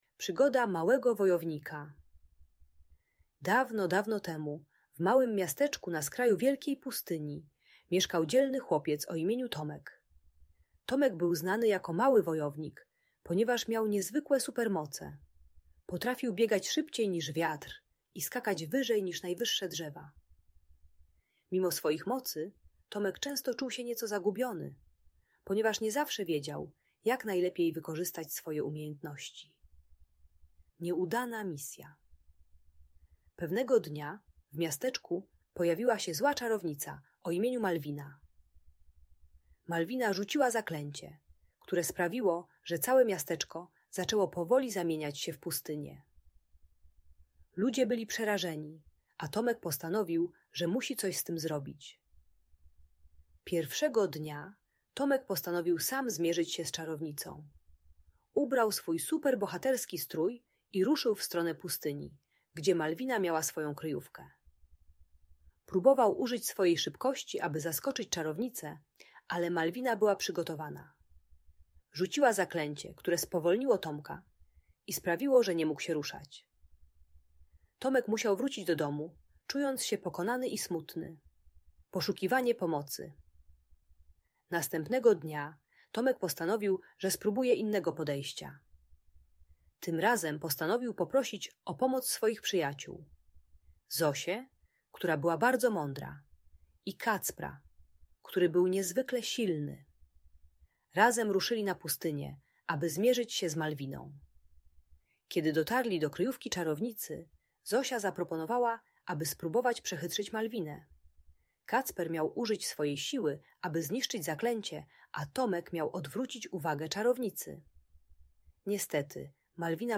Przygoda Małego Wojownika - Audiobajka dla dzieci